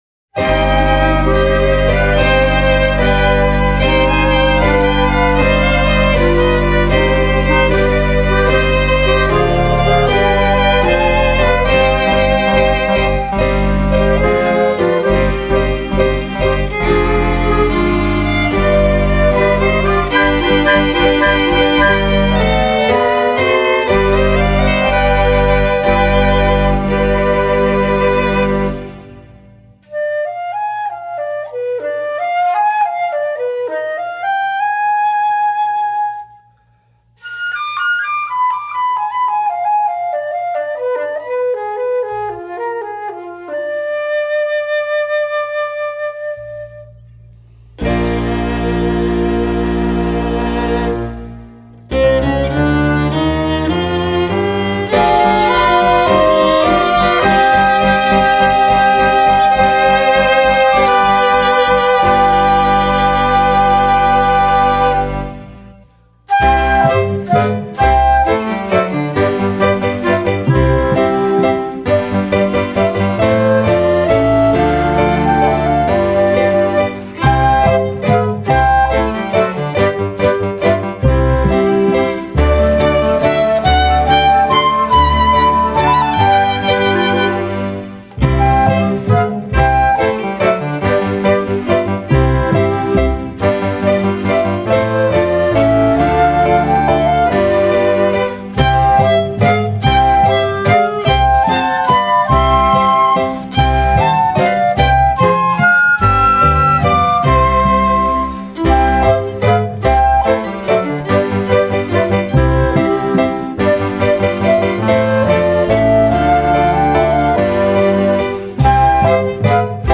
10:43:00   Valsa